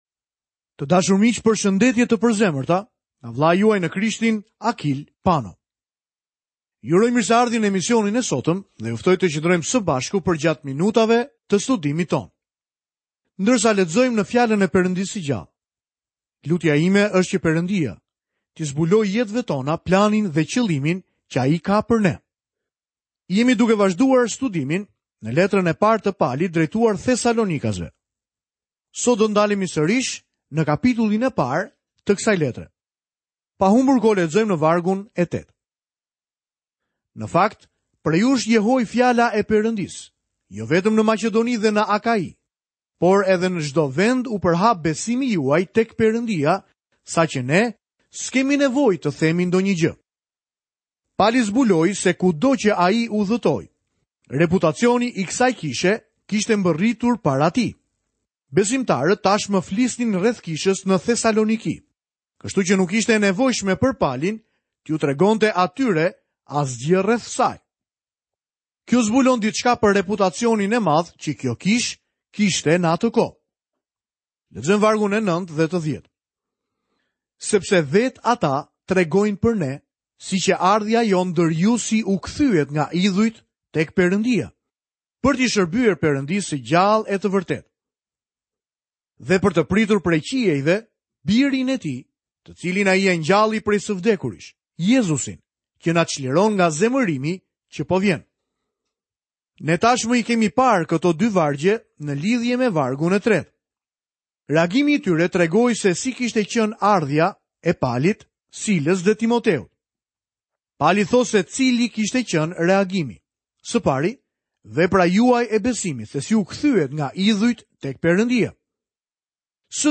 U planifikua që të ishte një program radiofonik ditor 30-minutësh, që në mënyrë sistematike ta udhëhiqte dëgjuesin përmes gjithë Biblës.